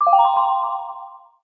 pause-retry-click.mp3